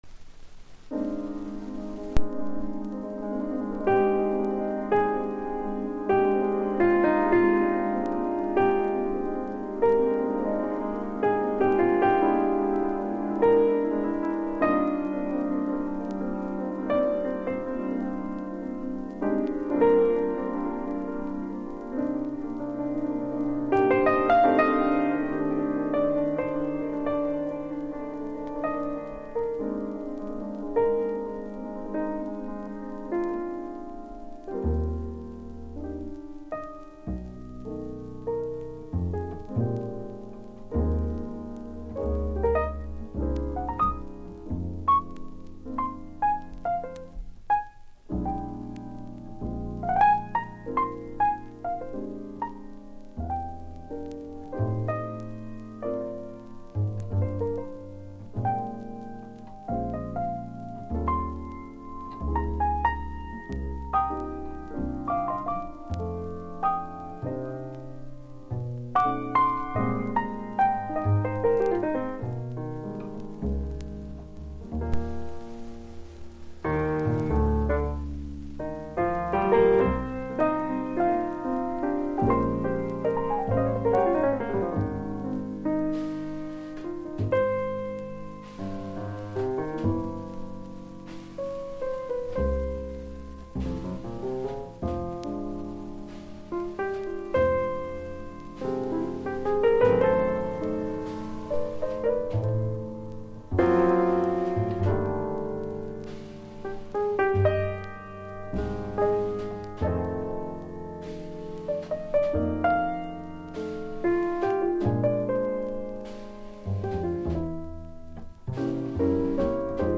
（2ピアノ+ベース+ドラム）